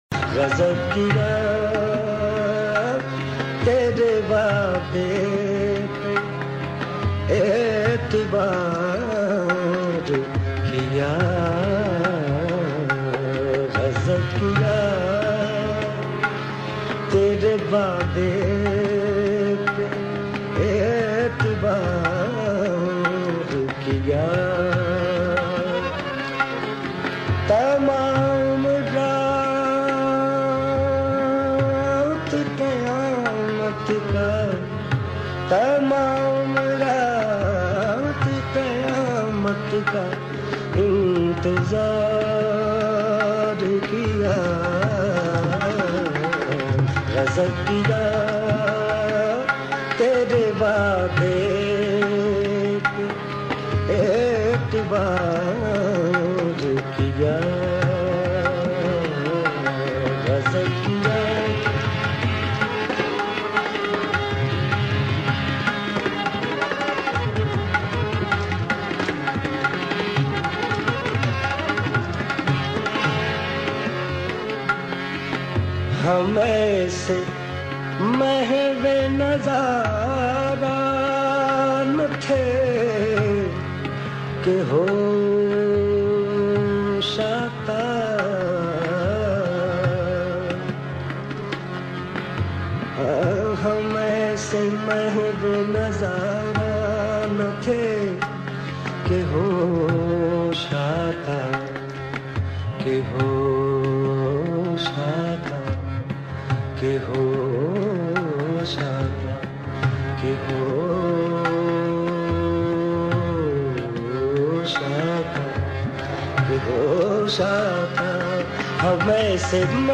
Sufi Songs